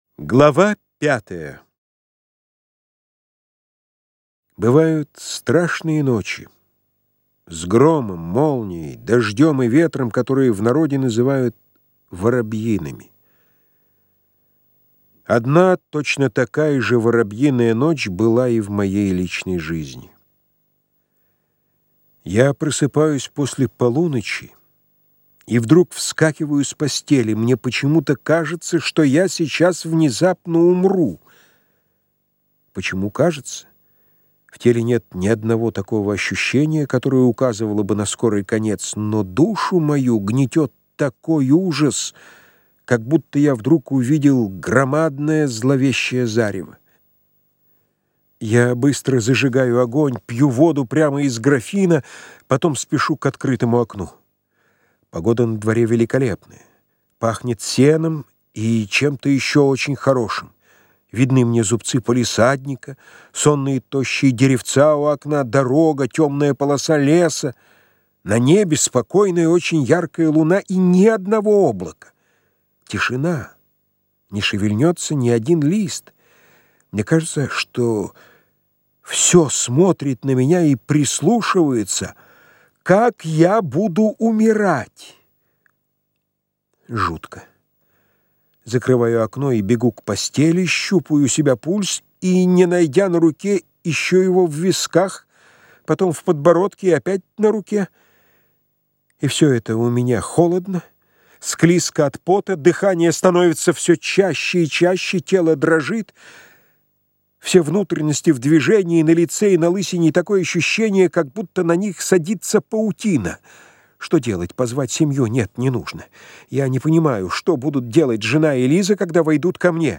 Аудиокнига Скучная история | Библиотека аудиокниг